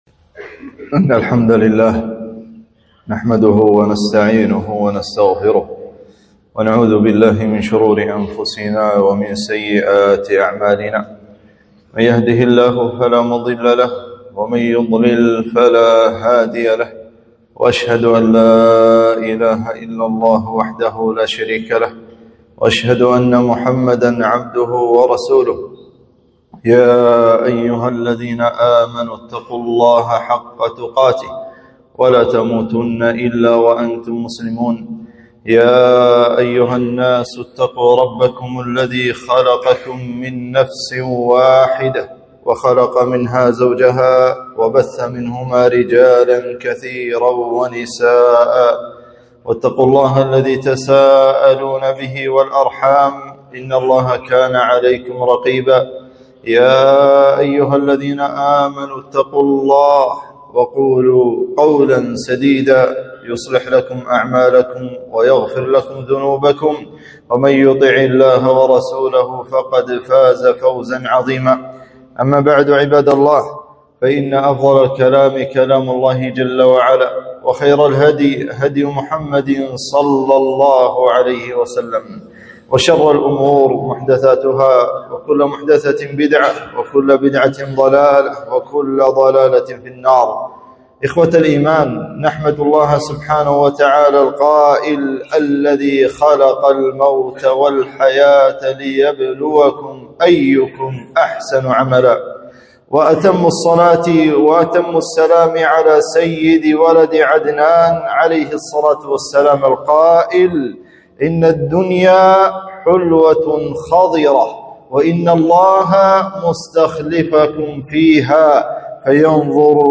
خطبة - حقارة الدنيا